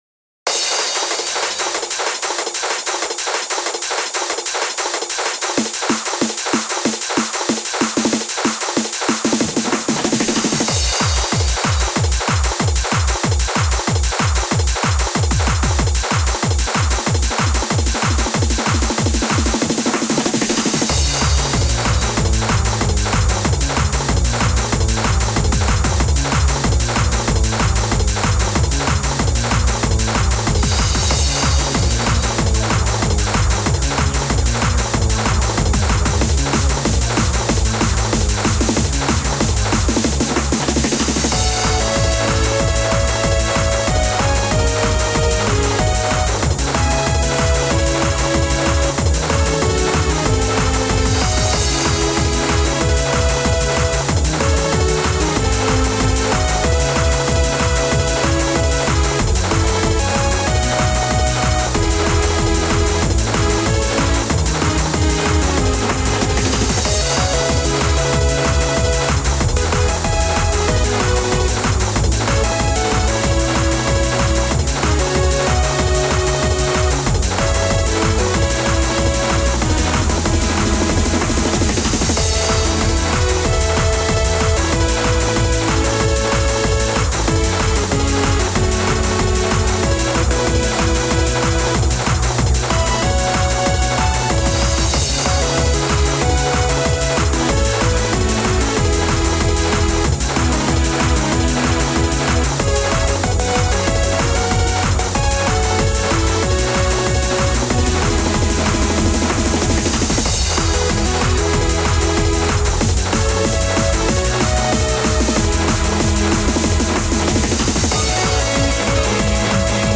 Trance core
昔からの作り方と現在使っている作り方を融合させたものが今回の曲です。